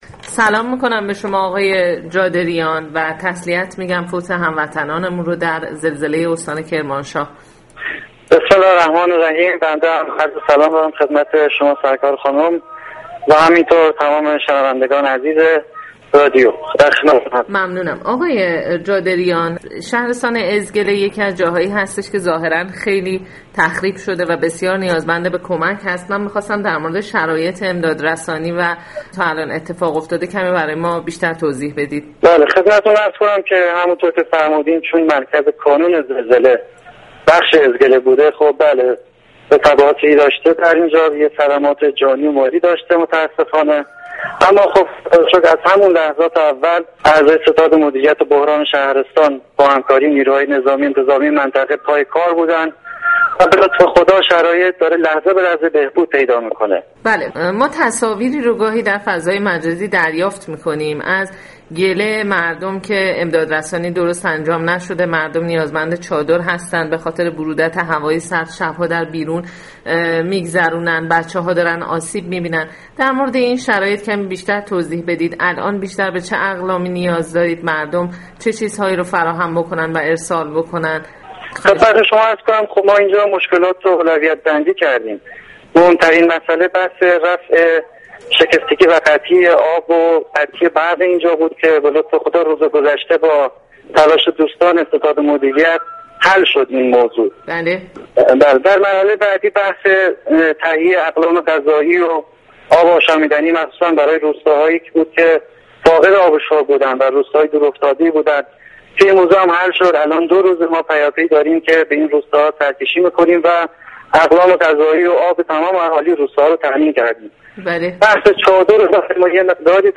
گفتگوی سایت رادیو فرهنگ با مهندس جادریان بخشدار شهرستان «اَزْگَله»